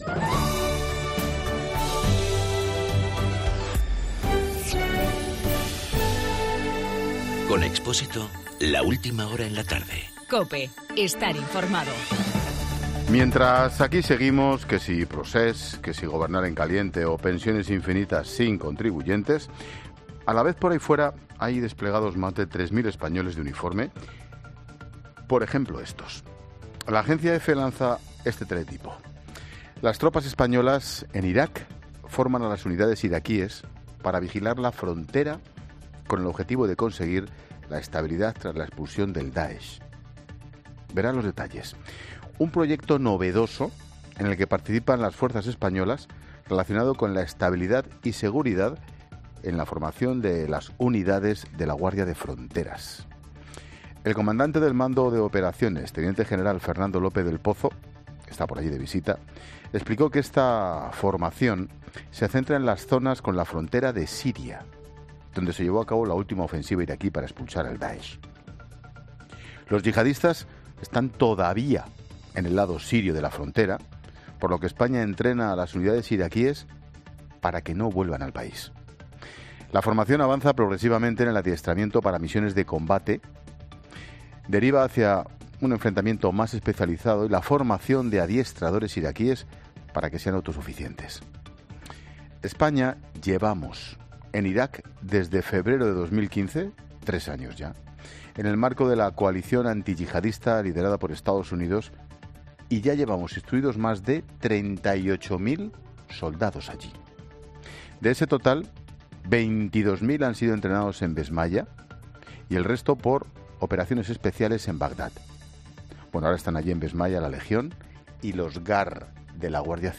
Monólogo de Expósito
El comentario de Ángel Expósito sobre la lucha en Irak contra el Daesh.